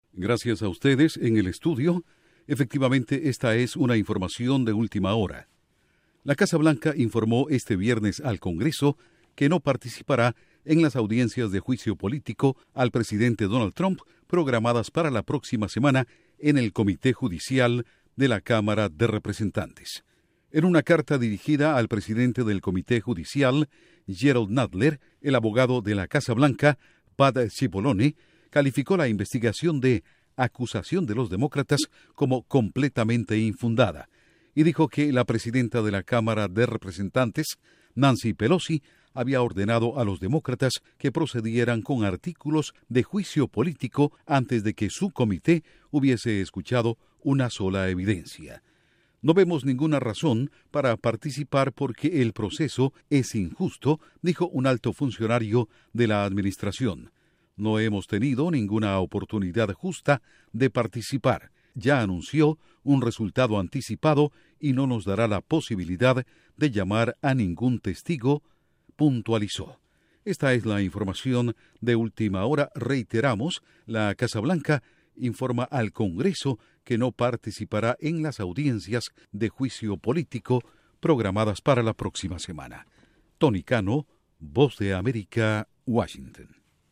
Última hora